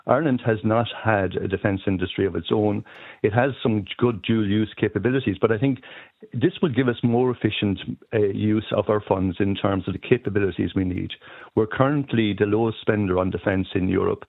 Mark Mellett, former Chief of Staff with the Irish Defence Forces, says it makes economic sense: